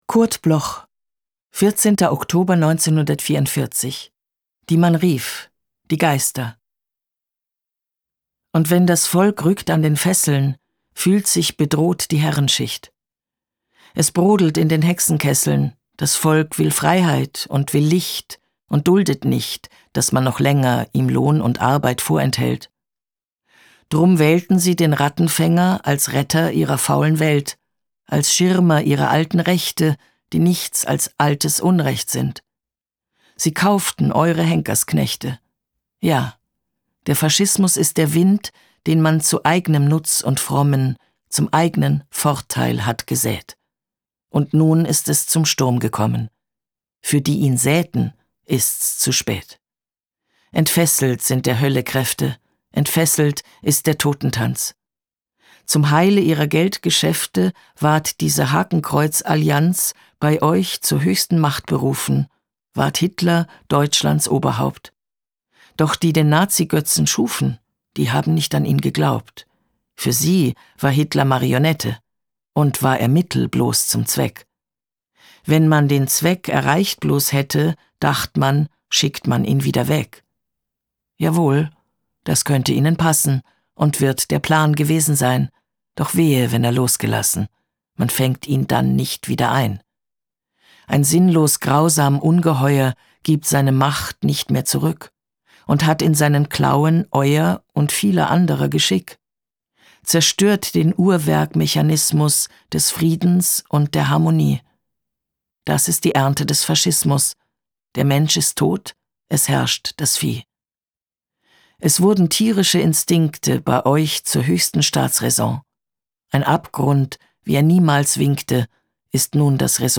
Recording: Speak Low, Berlin · Editing
Eva Mattes (* 1954) is een Duits-Oostenrijkse toneelspeelster, zangeres, stemartieste, audioboekvertelster en hoorspelactrice.